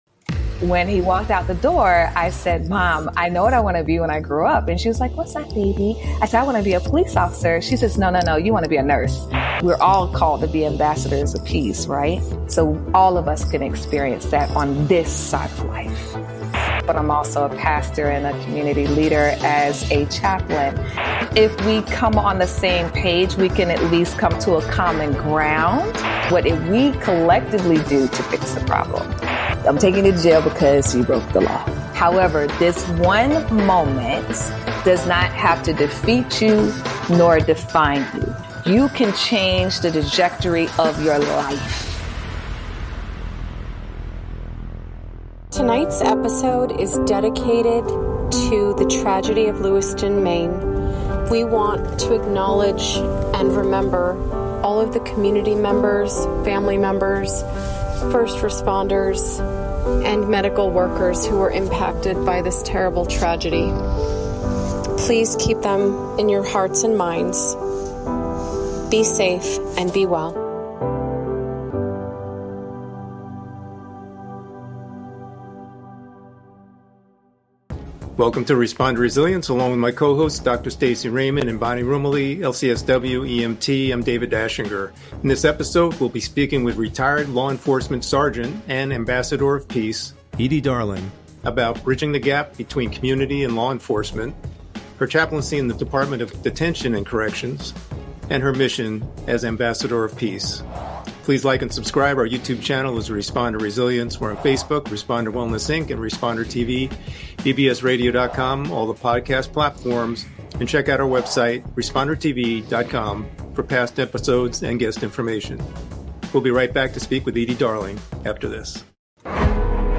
Talk Show Episode